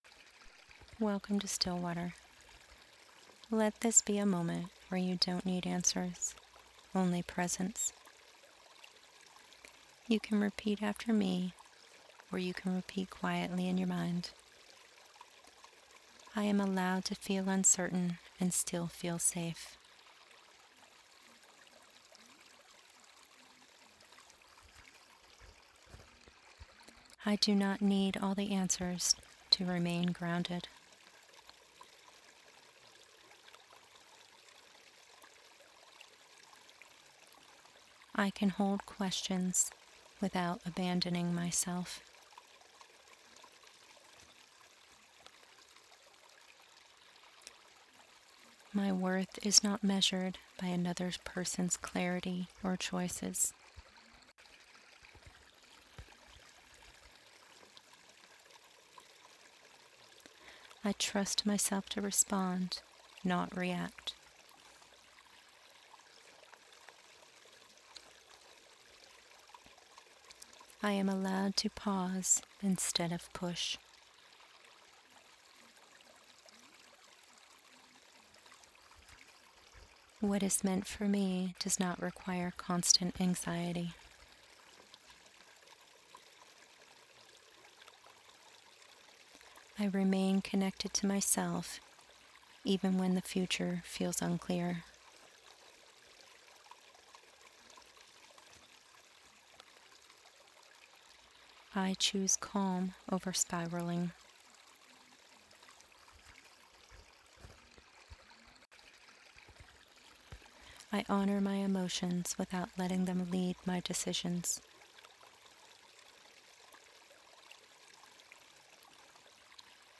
Here, you’ll find free affirmations and short guided meditations designed to help you pause, regulate, and return to calm in ways that feel realistic and accessible.